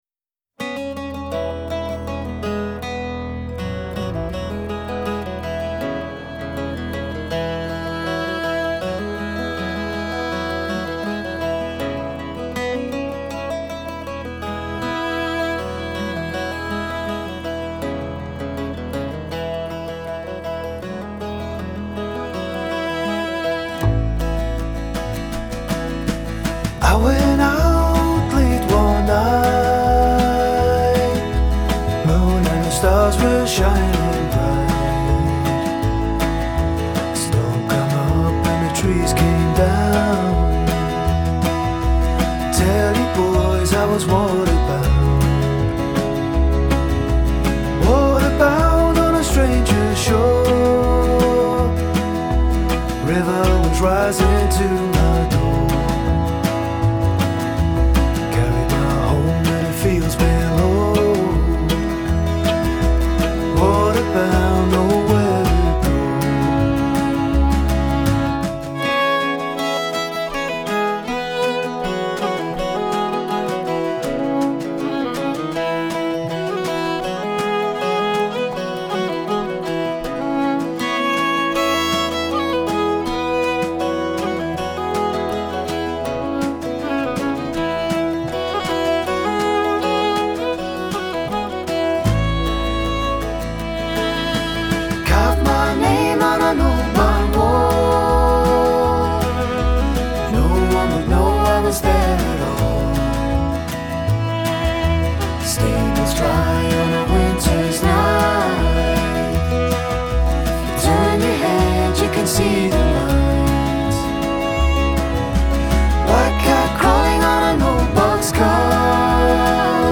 Genre: Folk / World /Celtic